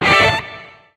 draco_atk_gui_13.ogg